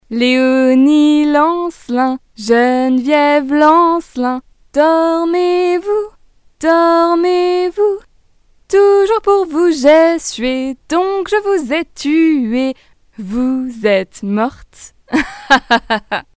女法107 法语女声干音 低沉|激情激昂|大气浑厚磁性|沉稳|娓娓道来|科技感|积极向上|时尚活力|神秘性感|调性走心|亲切甜美|感人煽情|素人|脱口秀